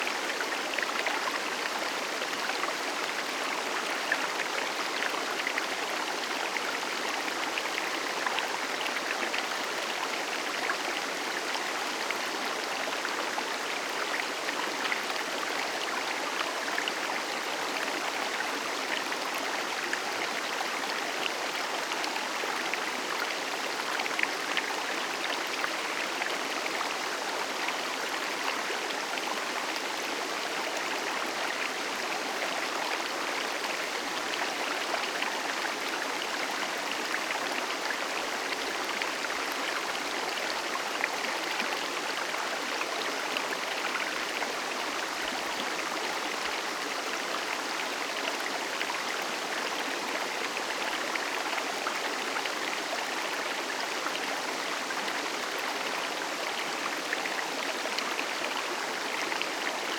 Waterfalls Rivers and Streams
River Stream Loop.ogg